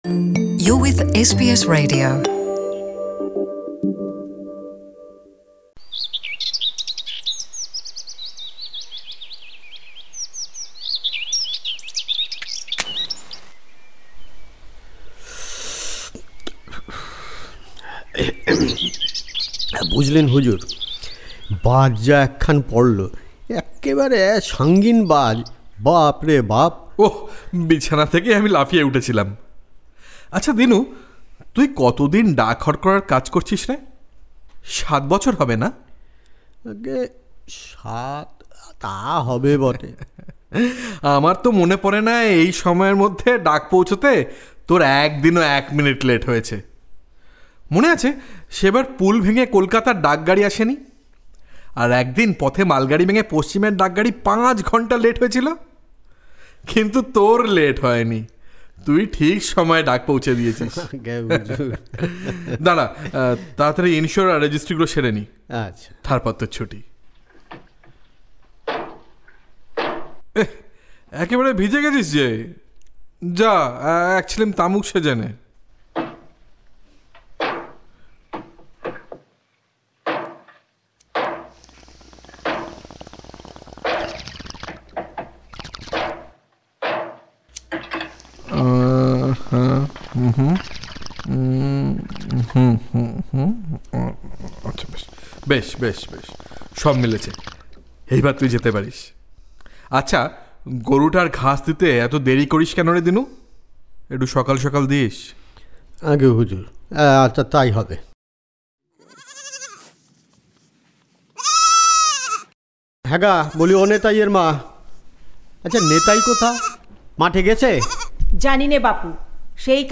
Radio Drama: Daak Harkara | SBS Bangla